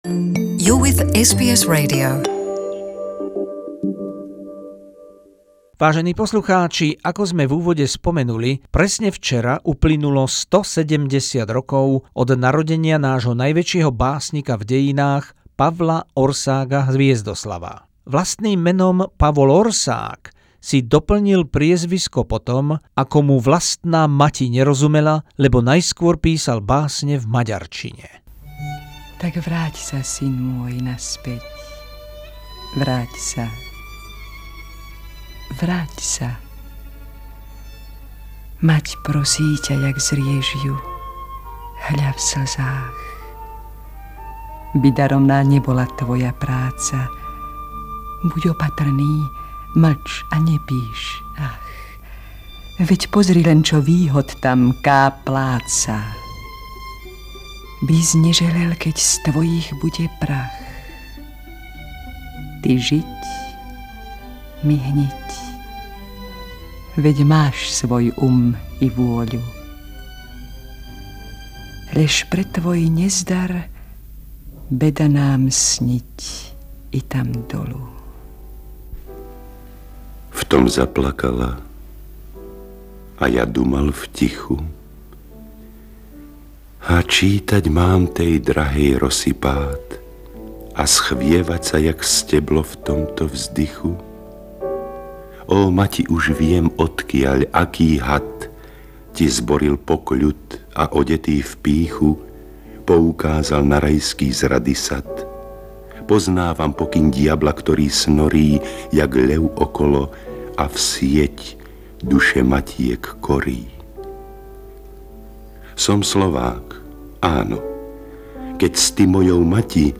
Jedinečné básne, úryvky a hlasy legendárnych slovenských hercov a recitátorov z diela velikána slovenskej poézie Pavla Országha Hviezdoslava k 170. výročiu jeho narodenia.